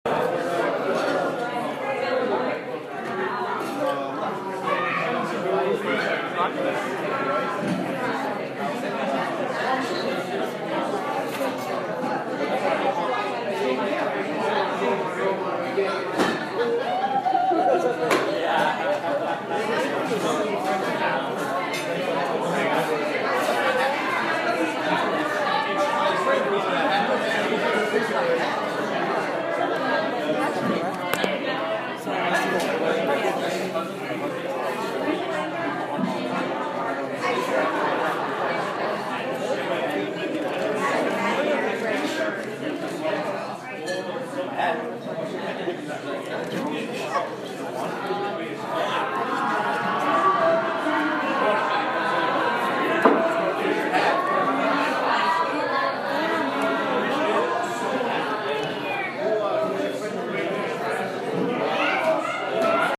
Field Recording-7
1.) Greenwood Inn (bar/restaurant) late at night
2.) People talking, Glasses clinking, Silverware on plates
Greenwood-Inn-Late-1.mp3